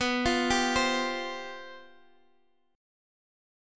Csus/B chord